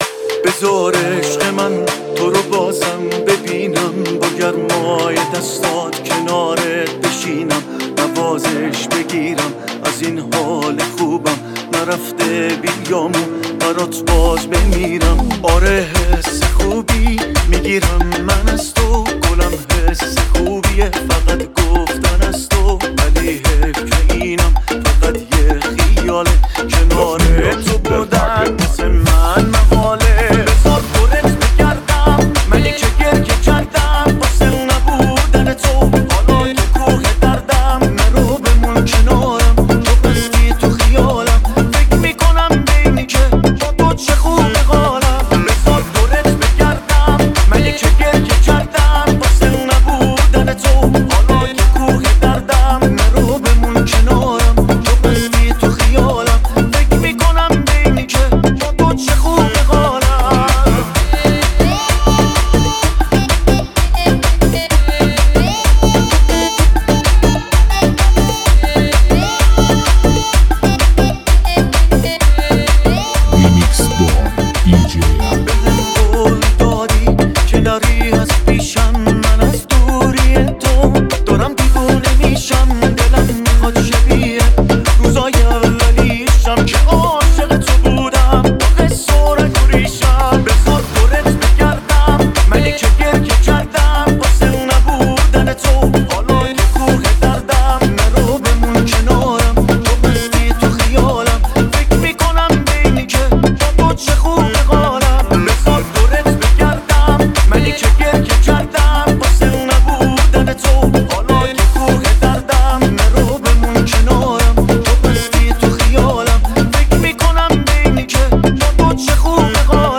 موسیقی پرانرژی و دلنشین در سبک‌های مختلف،